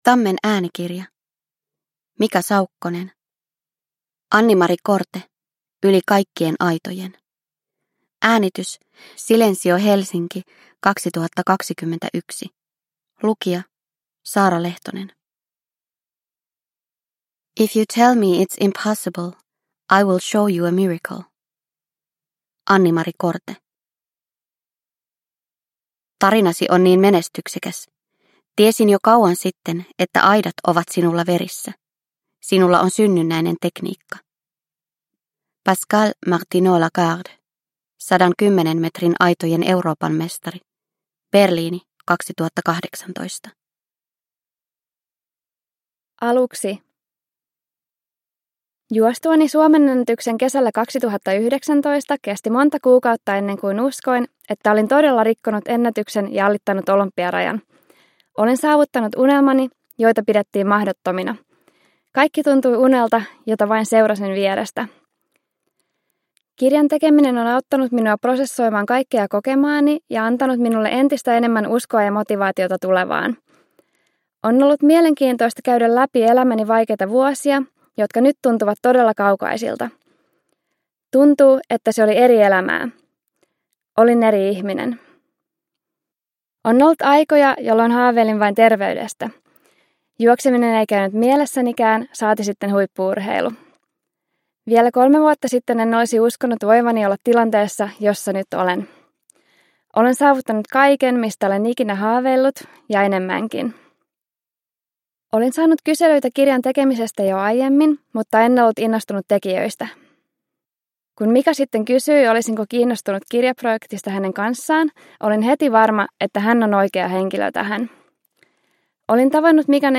Annimari Korte – Ljudbok – Laddas ner